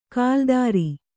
♪ kāldāri